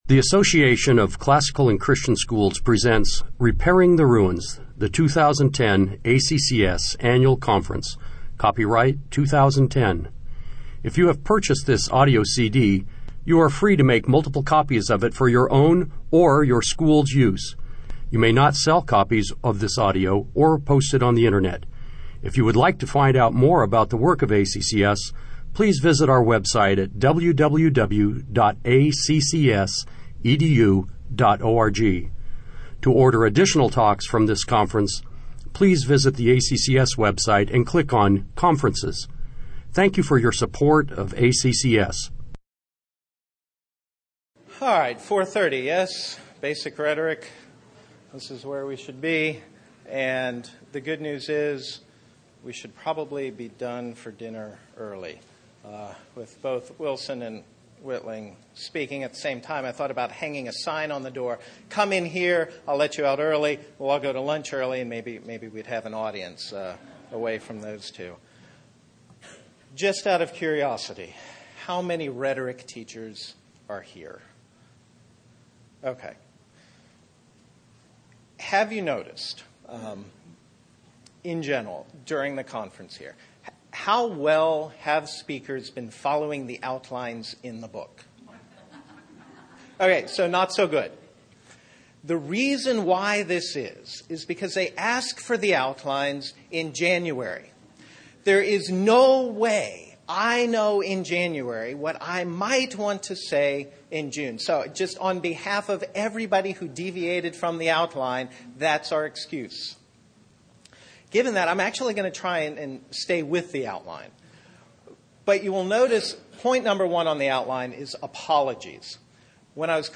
2010 Workshop Talk | 0:54:59 | 7-12, Rhetoric & Composition
The Association of Classical & Christian Schools presents Repairing the Ruins, the ACCS annual conference, copyright ACCS.